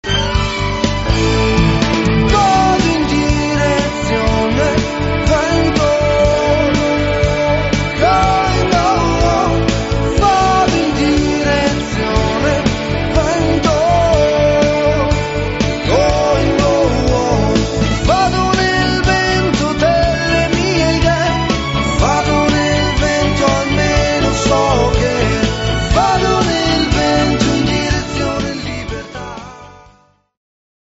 chitarra
tastiere
voce